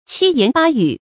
七言八語 注音： ㄑㄧ ㄧㄢˊ ㄅㄚ ㄧㄩˇ 讀音讀法： 意思解釋： 形容人多語雜。